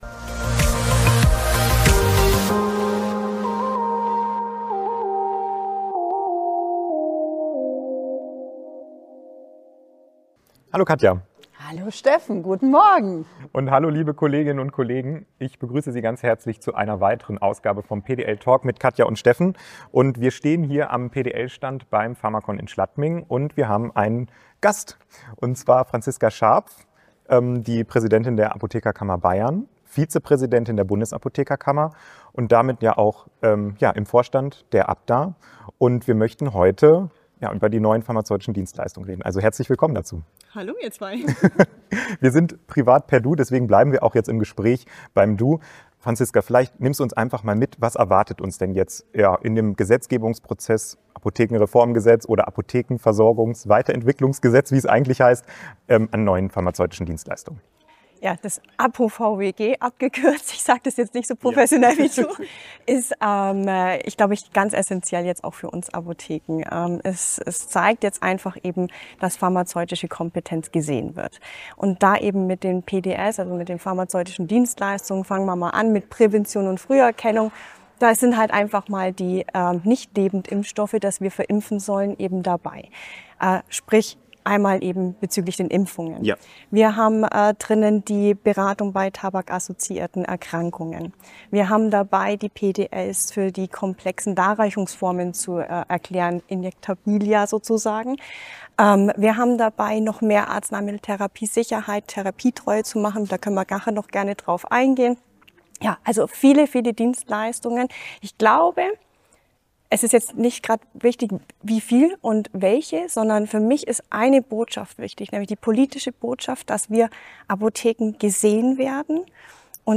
Live vom pharmacon